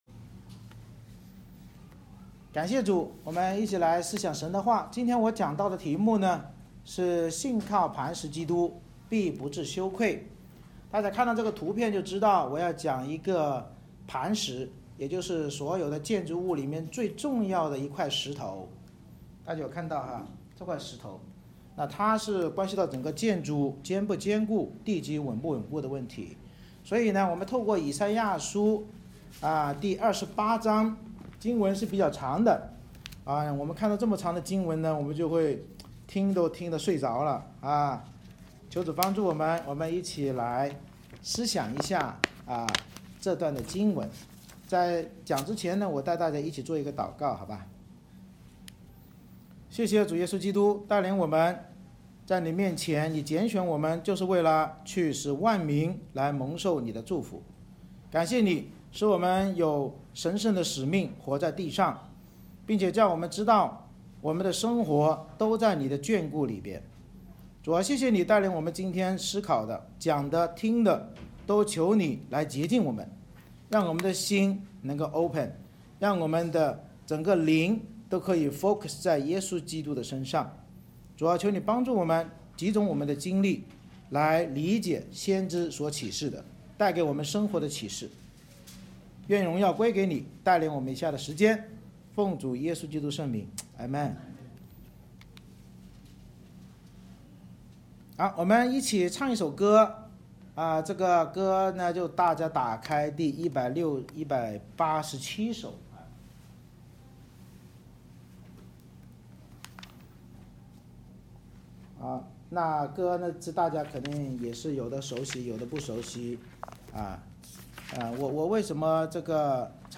以赛亚书28:1-29 Service Type: 主日崇拜 神藉以赛亚先知警告南国要以北国灭亡为鉴戒，启示我们不可沉迷世界享乐与依靠人的权势或帮助，而要听真道并信靠磐石基督耶稣就必不羞愧。